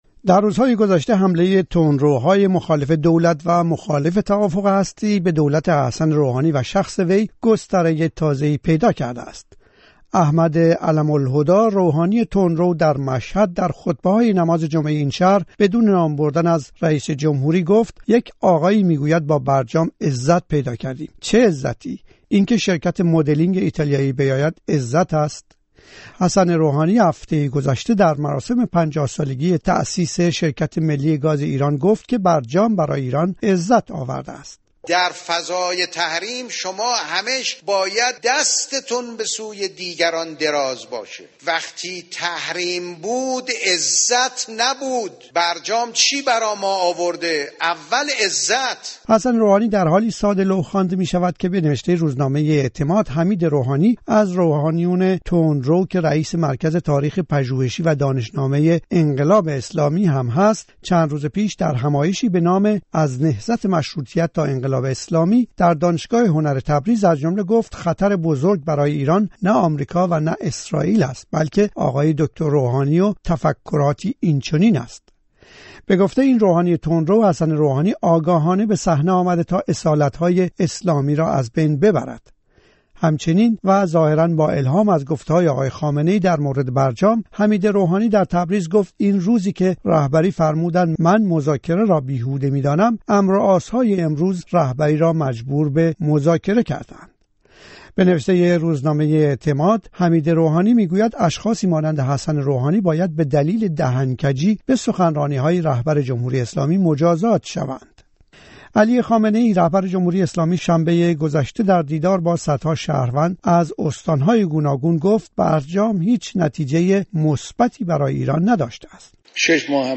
خبرها و گزارش‌ها